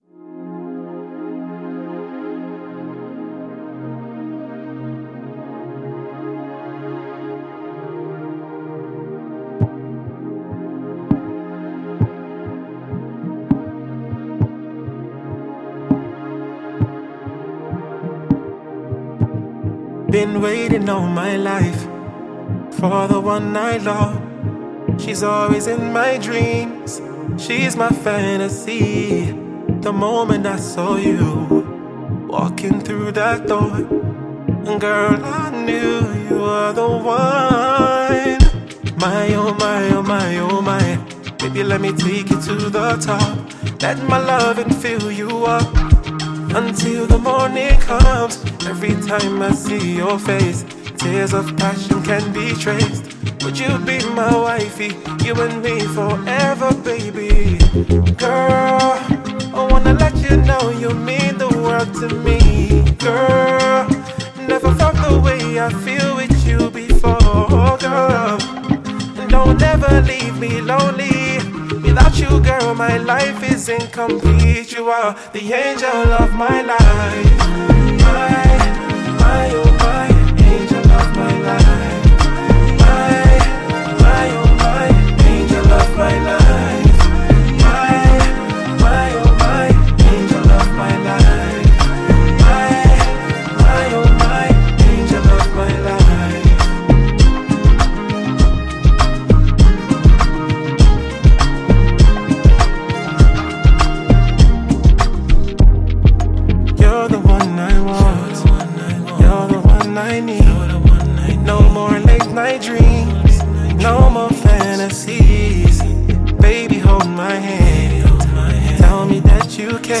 Talented Nigerian singer, lyricist and performer